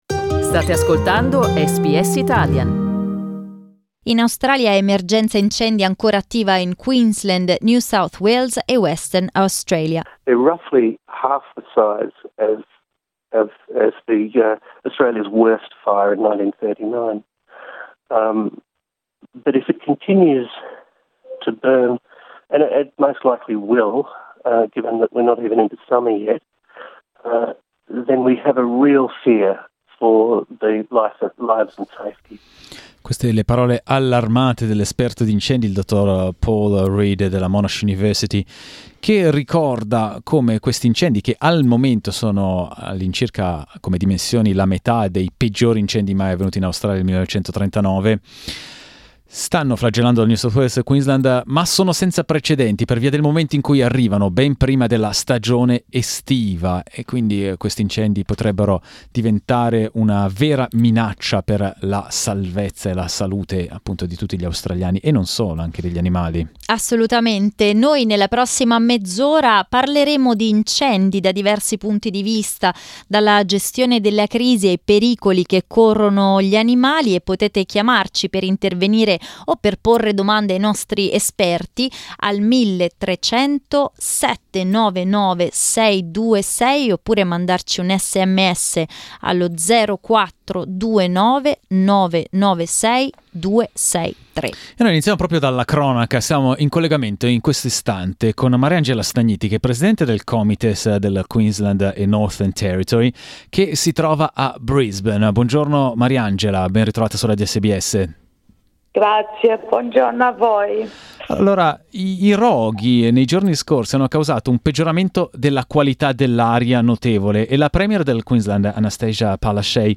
The current bush fire emergency in Australia has been aggravated by alleged arson cases. Meanwhile the air in Brisbane is of very low quality due to the bush fire smoke pushed northward by the wind. We talked about it this morning with listeners and experts.